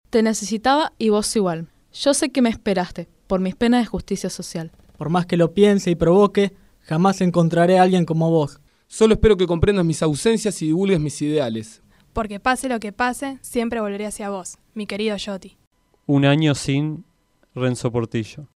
Participaron en la locución: